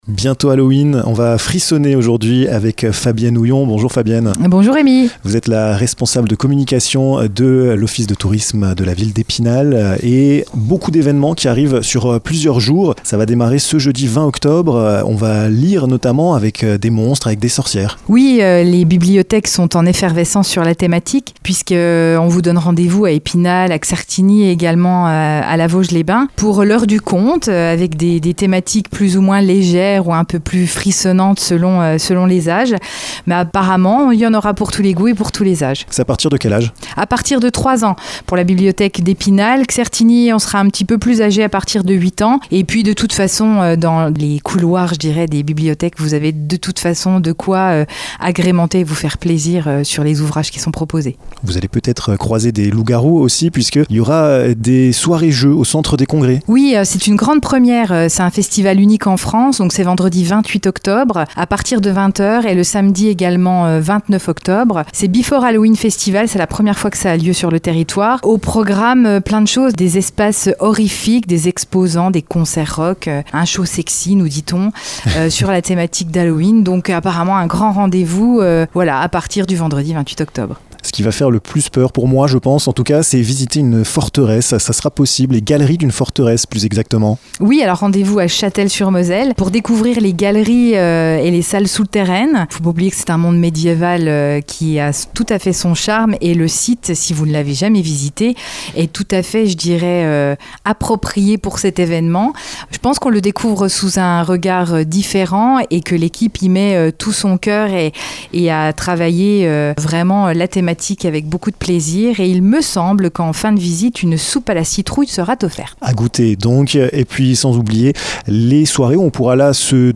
%%La rédaction de Vosges FM vous propose l'ensemble de ces reportages dans les Vosges%%